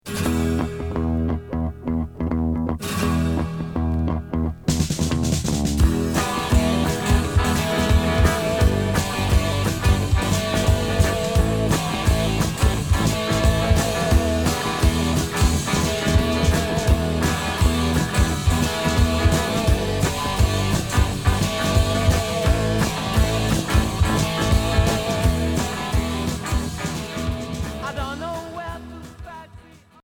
Rock blues Unique 45t retour à l'accueil